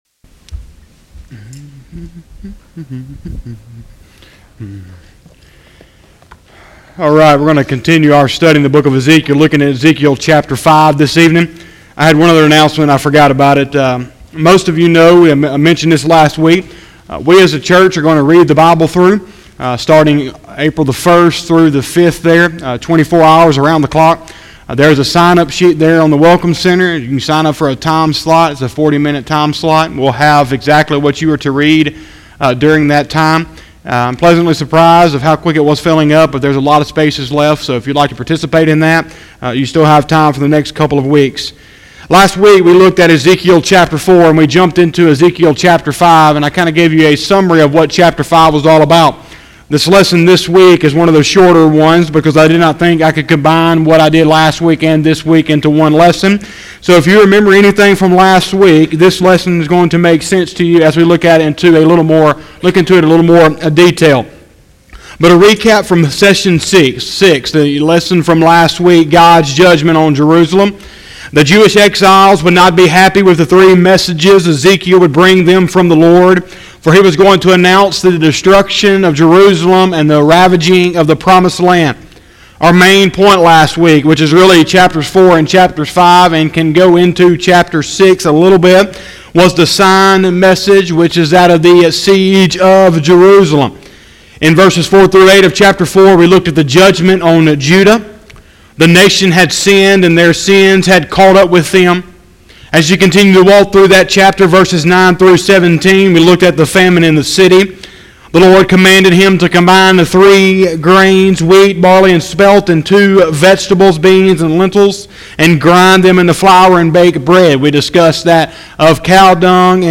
03/15/2020 – Sunday Evening Service